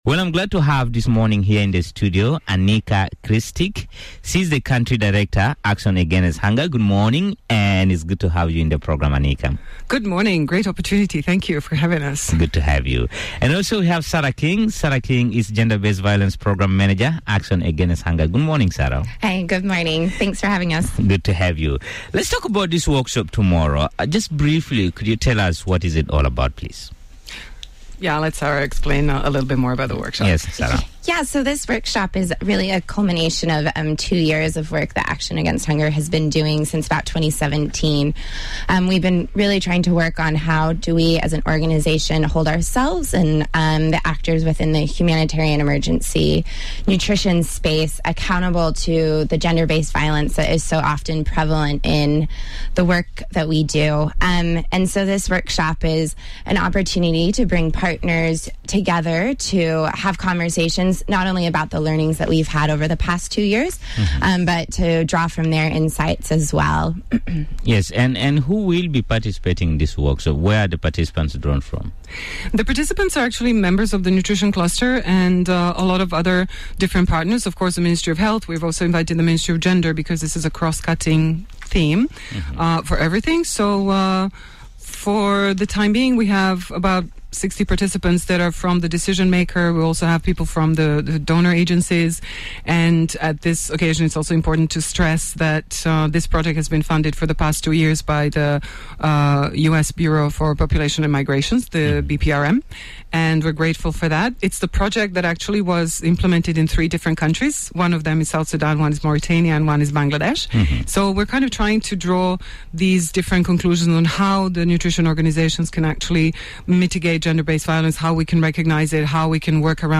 She spoke to Miraya Brakfast Show this morning.